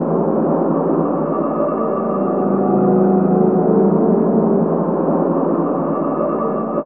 Index of /90_sSampleCDs/Chillout (ambient1&2)/13 Mystery (atmo pads)
Amb1n2_v_pad.wav